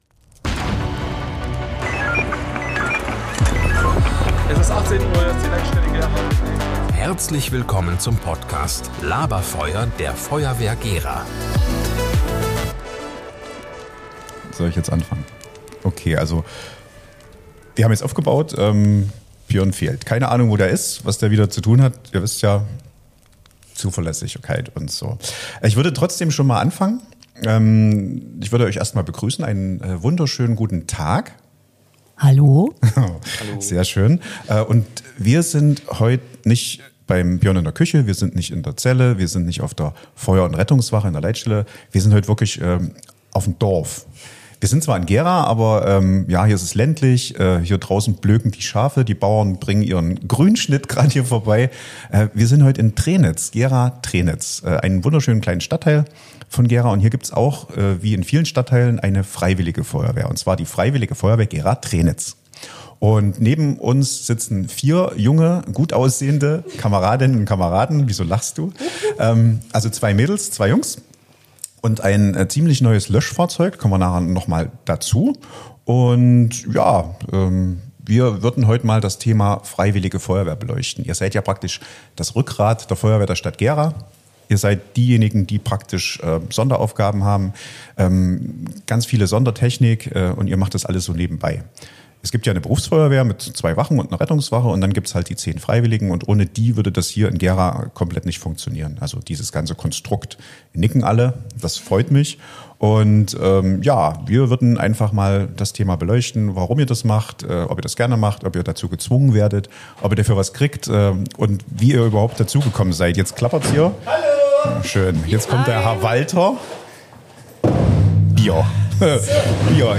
In einer lockeren, ehrlichen Runde sprechen wir mit Frauen und Männern der Feuerwehr über: Herausforderungen im Ehrenamt Zeit, Verantwortung und echten Zusammenhalt Warum man manchmal flucht – aber trotzdem bleibt Und wieso es am Ende einfach unfassbar viel Spaß macht Außerdem klären wir eine der wichtigsten Fragen rund um den Straßenverkehr: Dürfen Mitglieder der Freiwilligen Feuerwehr eigentlich mit Blaulicht unterwegs sein – oder wie kommen sie im Einsatzfall schnell und vor allem rechtssicher zum Gerätehaus?